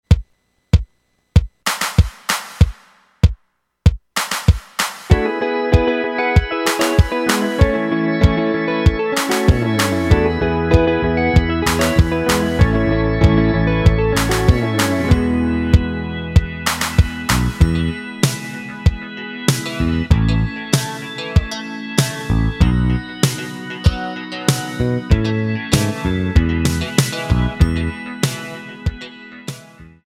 klick & play MP3/Audio demo